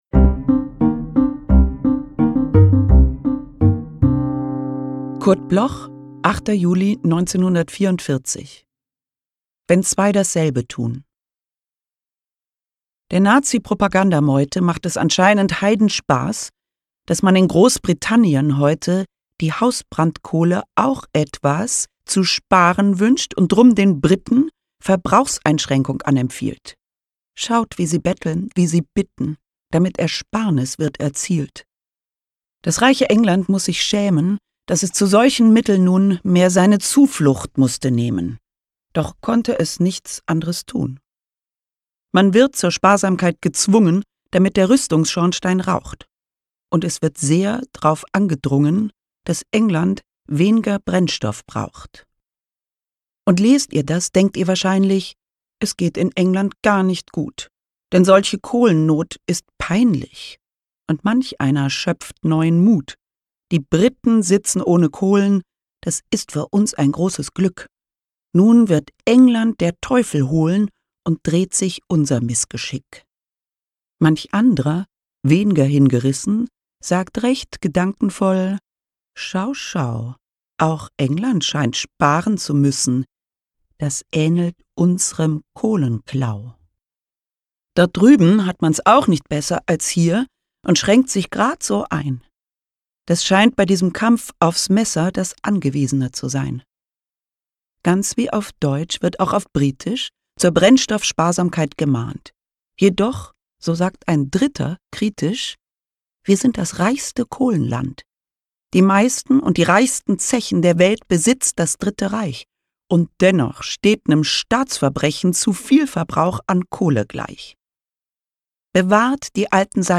Caroline Peters (* 1971) is a German actress and narrator of radio plays.
Caroline-Peters-Wenn-zwei-dasselbe-tun-…_raw_mit-Musik.m4a